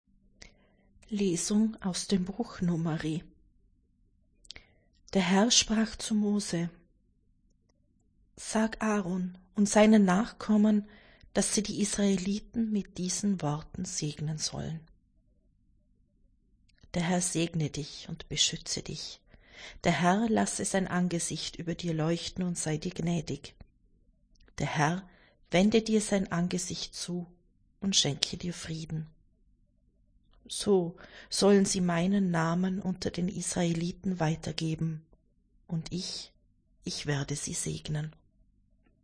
C-Neujahr-1.-Lesung-1.1.25.mp3